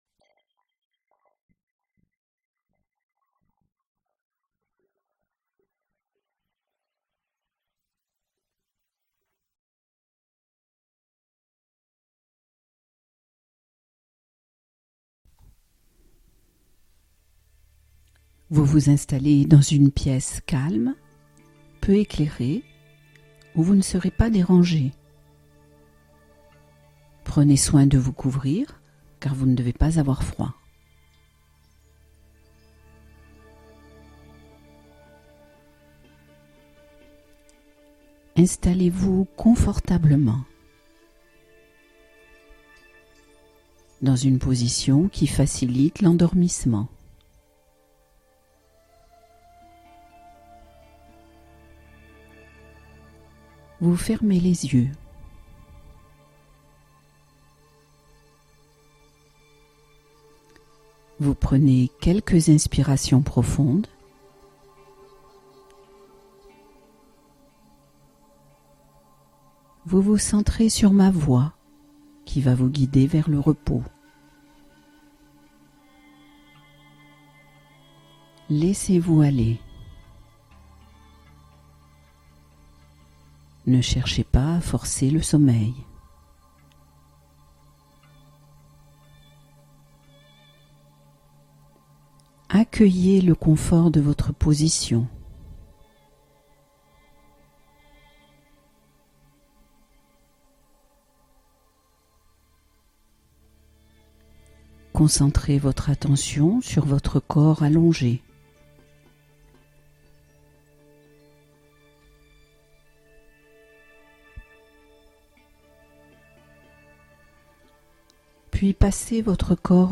Sommeil réparateur retrouvé : la relaxation guidée qui efface l'insomnie durablement